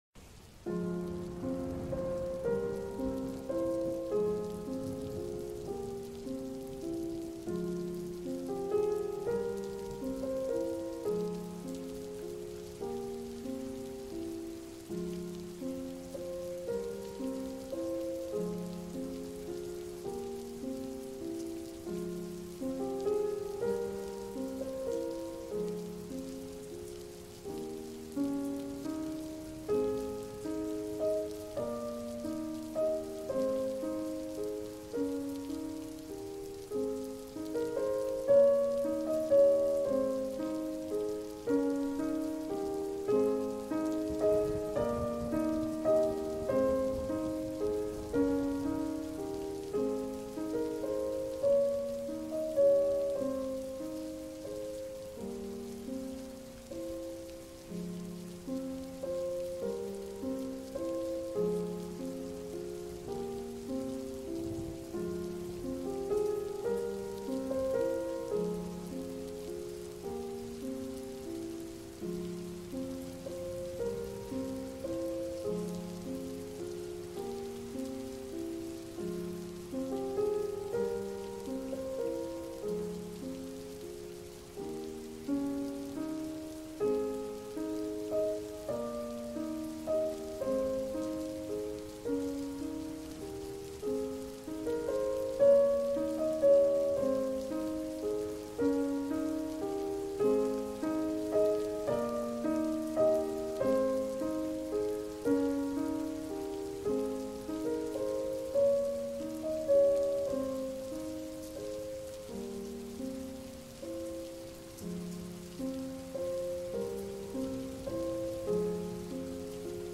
集中力を高めるガンマ波音